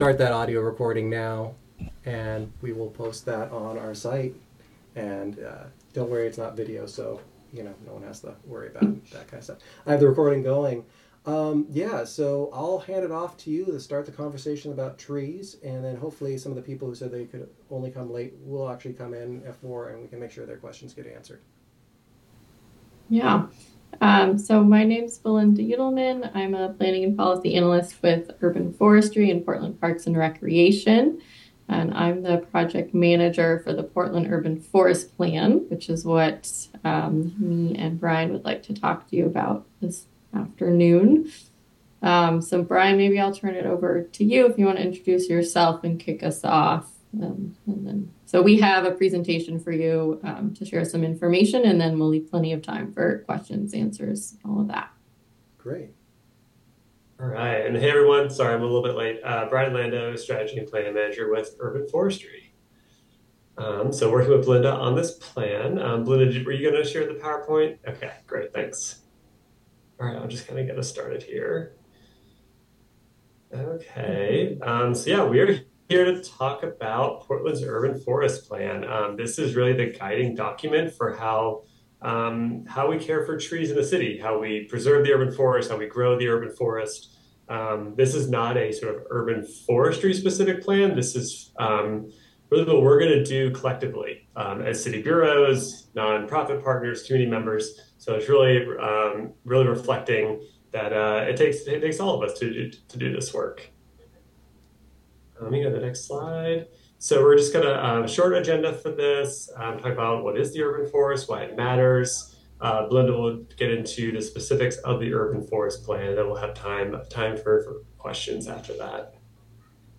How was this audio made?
Attendees share information and perspectives from other meetings, advisory groups, and community outreach.